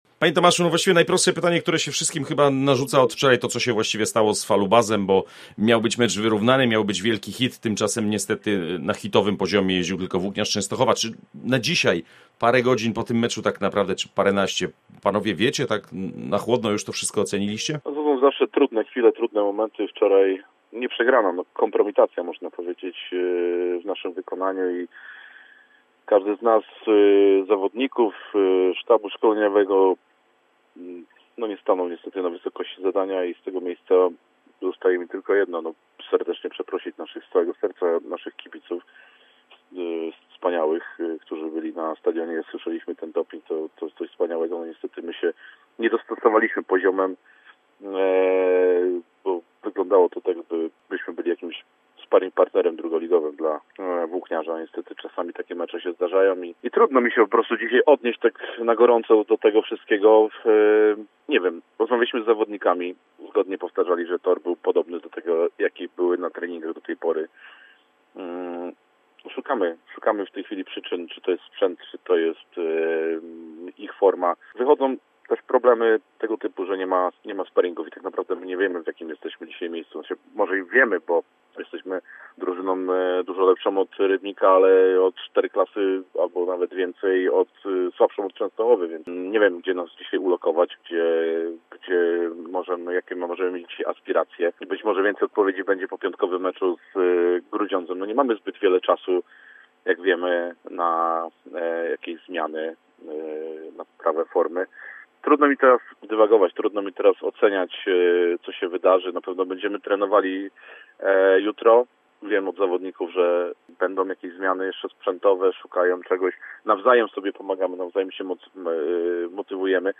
Jak ten mecz ocenia kierownictwo zespołu, jakie przyczyny takiej porażki widzi? O tym rozmawiamy